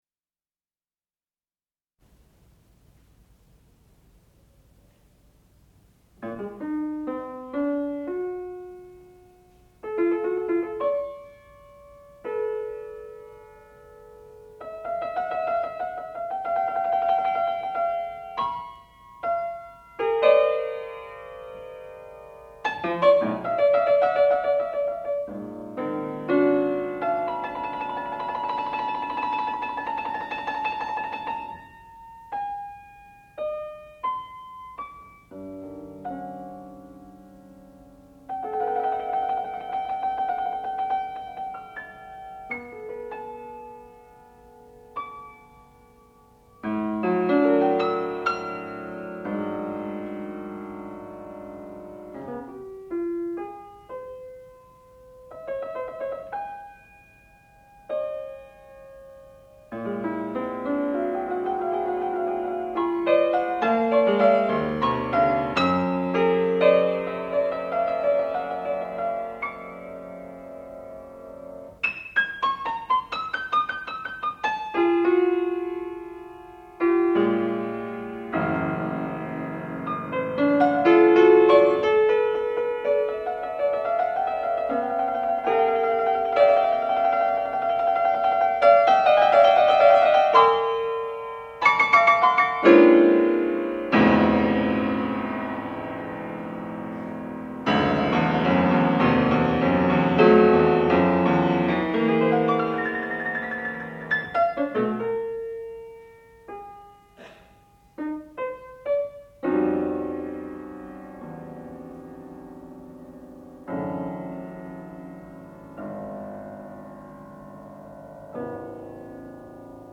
sound recording-musical
classical music
Graduate Recital